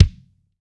RL-N KICK.wav